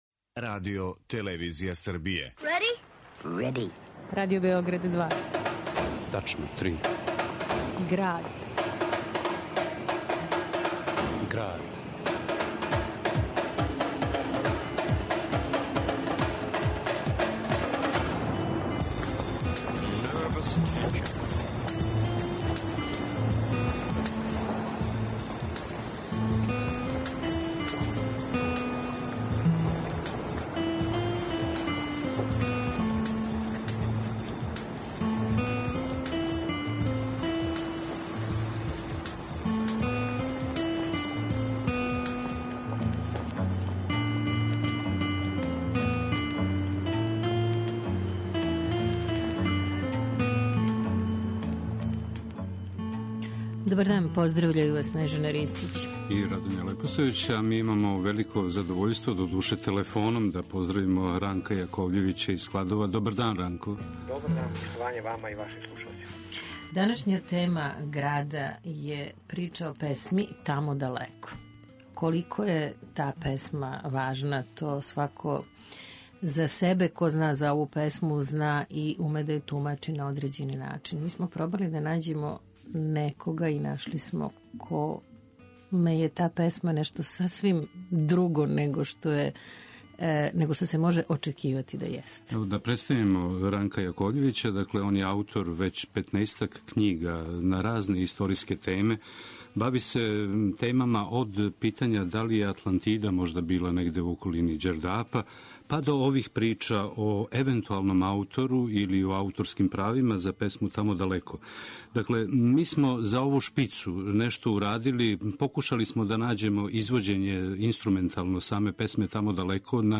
Како је "Тамо далеко" стигла до Холандије, како је постала успаванка? О томе у документарно-драмској емисији